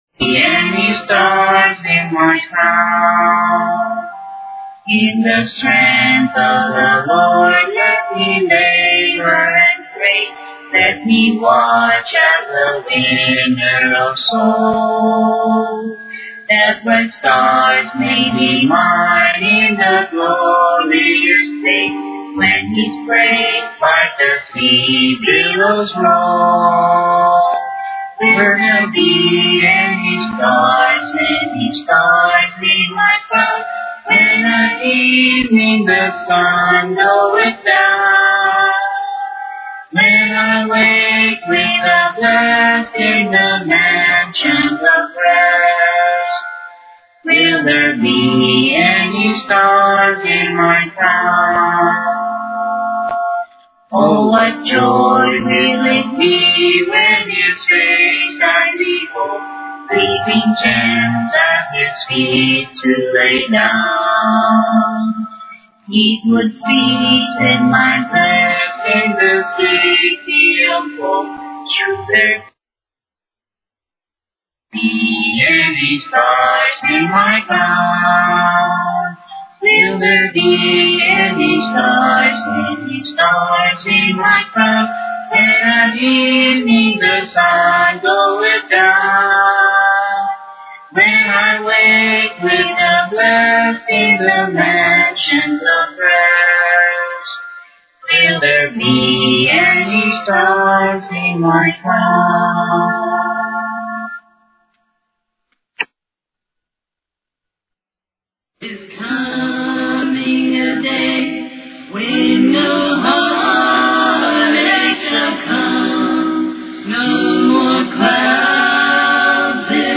Click here for the MP3 sermon based on this page Click here to view this page as a video Click here for a Letter from G.B Starr of a conversation regarding Melchizidek with Ellen White (Who was G.B. Starr?